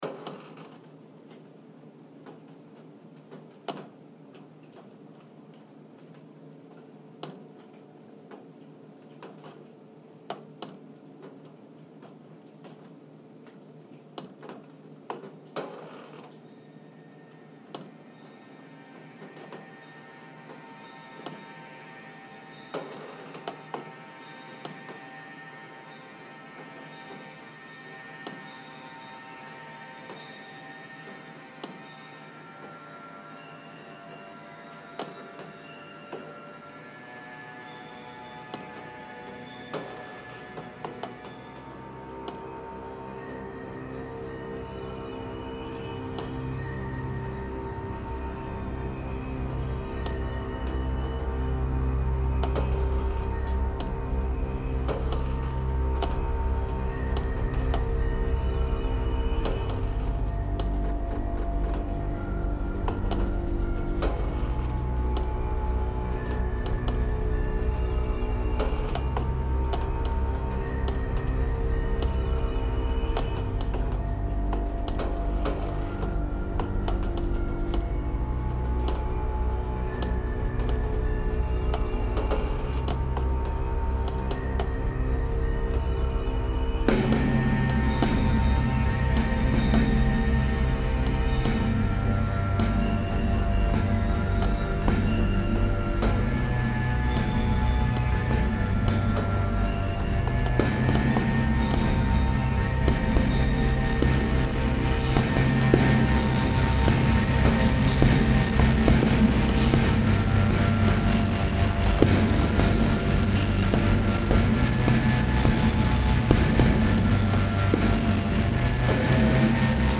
Und zwar habe ich die Grillen, die man bei den Regentropfen im Hintergrund hören konnte im letzten Upload per Equalizer stark gedämpft.
Deshalb, hier das Stück nochmal mit Grillenzirpen am Ende.